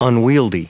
Prononciation du mot unwieldy en anglais (fichier audio)
Prononciation du mot : unwieldy